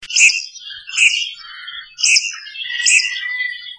Nette rousse